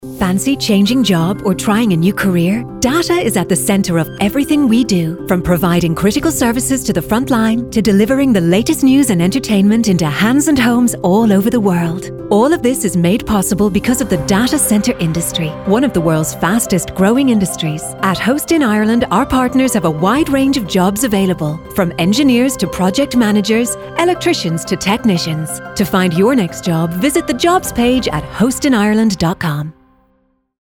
Host in Ireland Radio Ad campaign aimed at attracting and recruiting talent to the data centre industry is now live